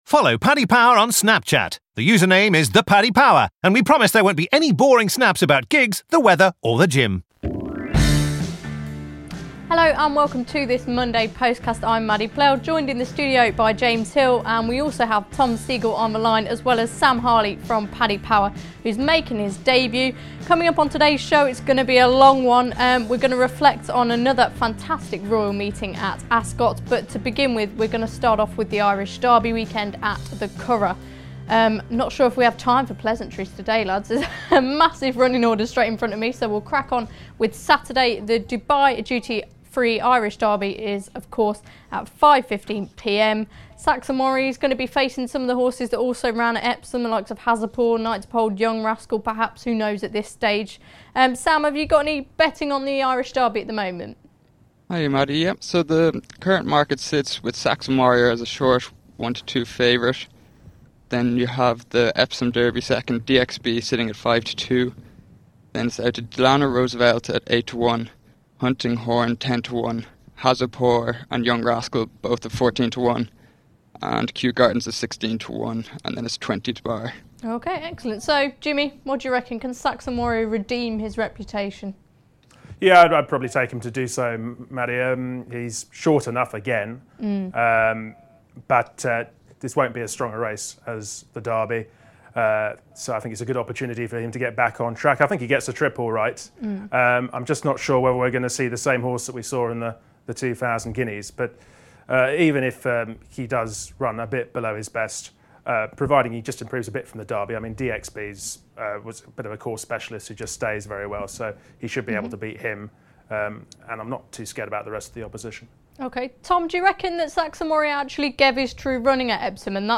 Following another fantastic Royal meeting at Ascot the Postcast panel reflect on their highlights from the meeting and cast an eye forward to the rest of the season.